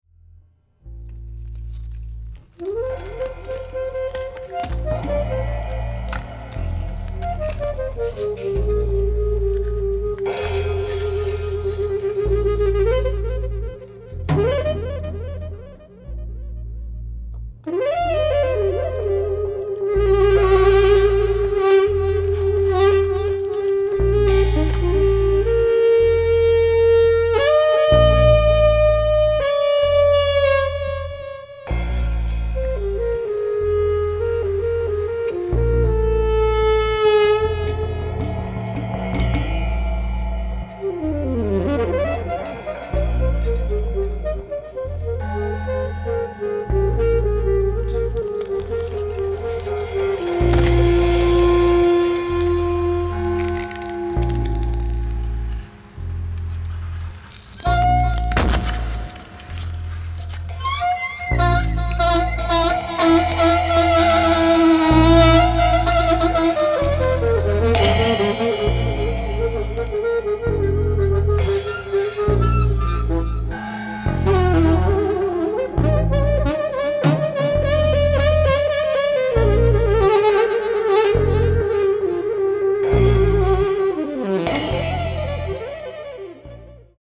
Alto Saxophone, Synthesizer [Juno 6], Percussion, Voice
Cymbal (03)